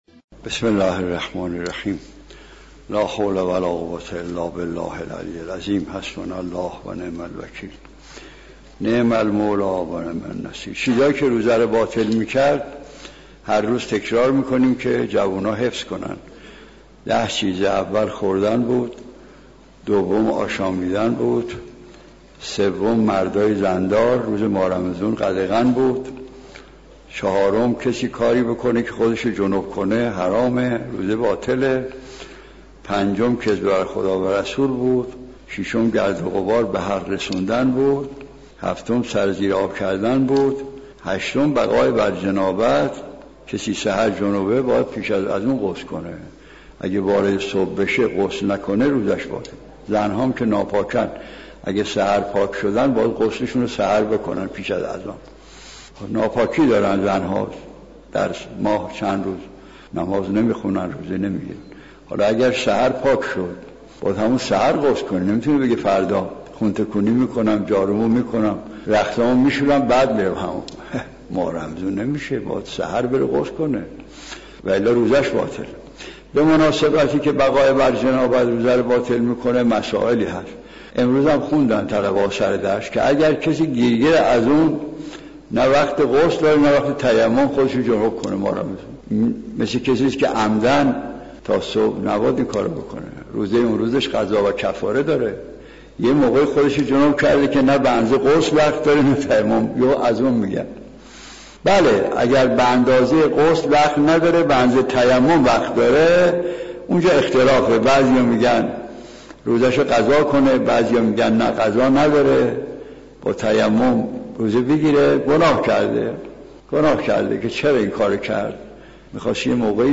دعای روز هفدهم ماه مبارک رمضان با تفسیر آیت‌الله مجتهدی تهرانی + صوت